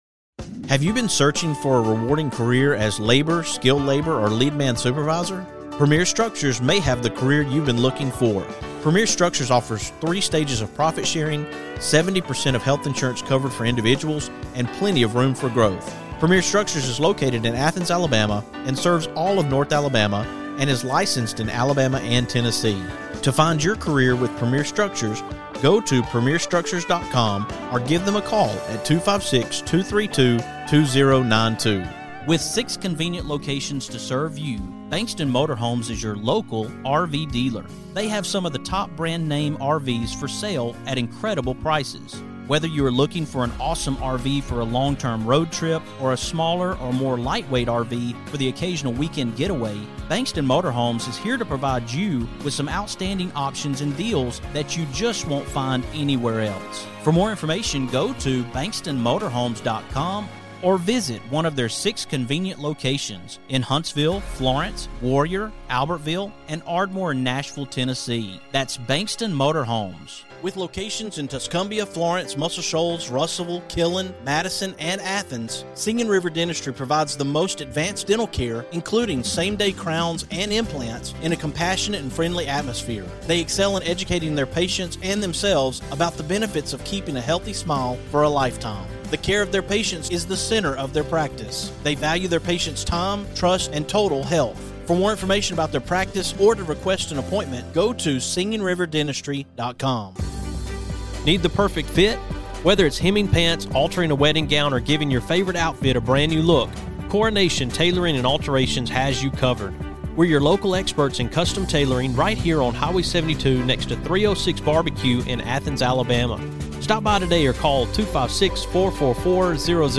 Two conversations.